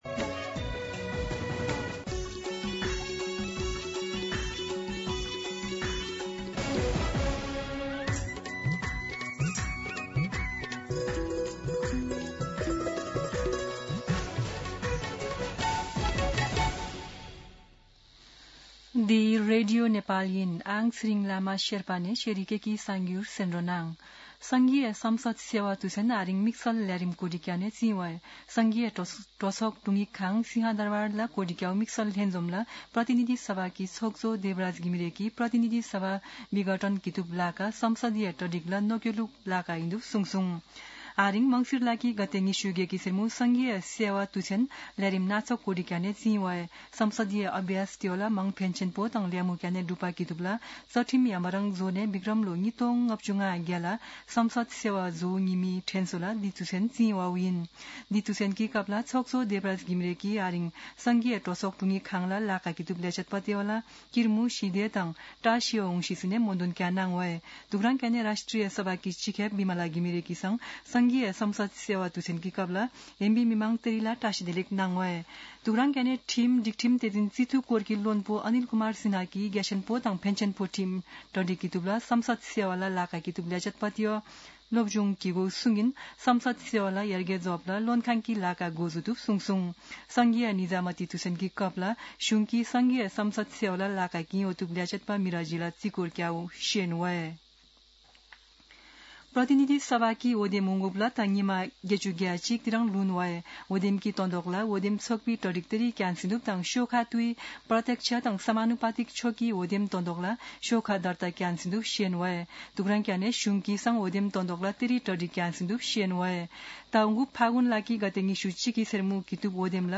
शेर्पा भाषाको समाचार : २८ मंसिर , २०८२
Sherpa-News-8-28.mp3